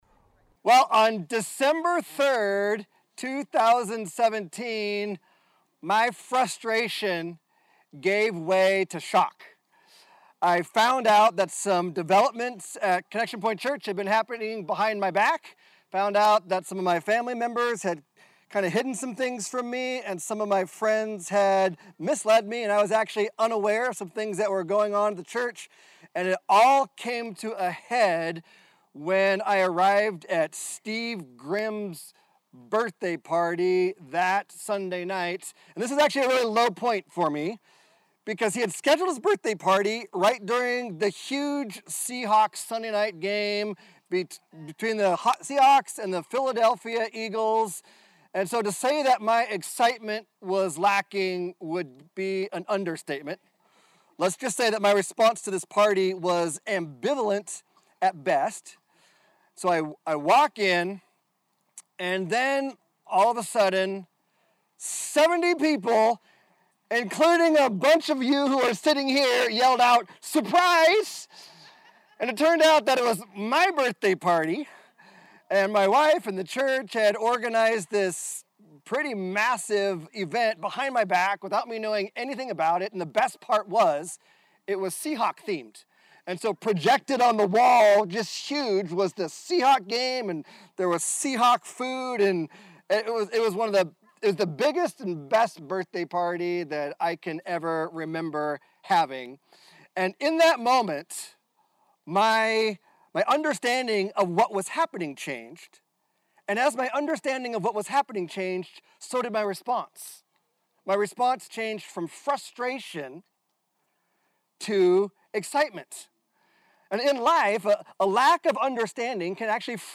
July 18, 2021 - Baptism Sunday - Today we baptized six of our people.